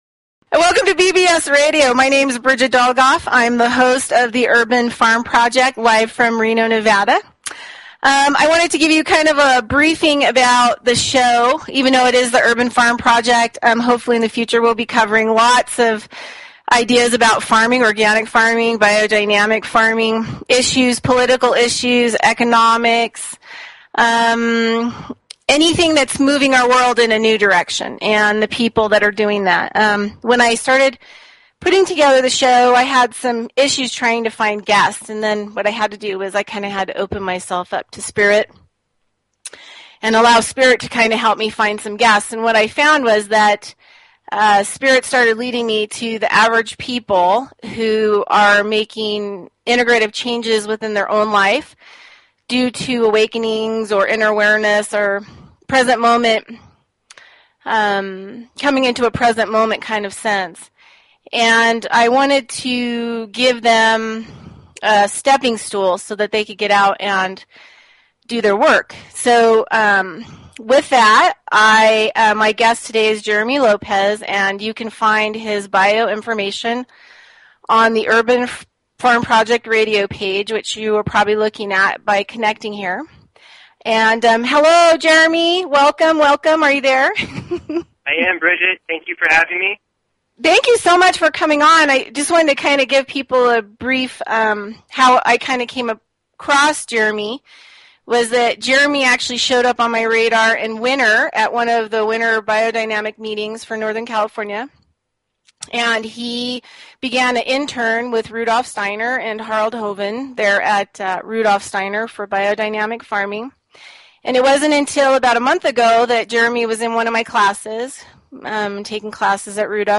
Talk Show Episode, Audio Podcast, The_Urban_Farm_Project and Courtesy of BBS Radio on , show guests , about , categorized as